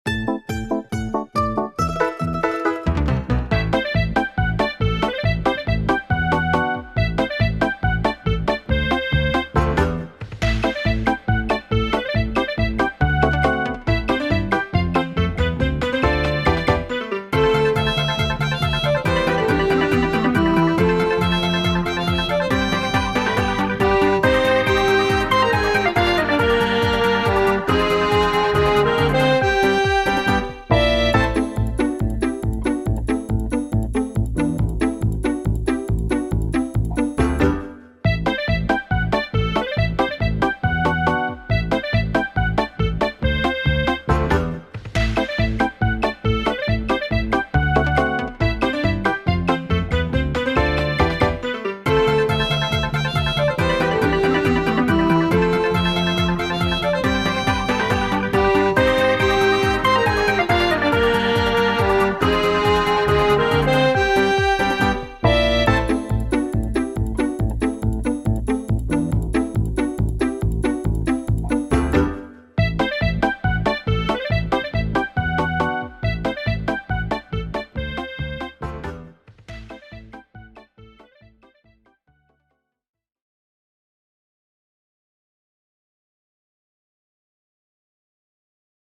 OST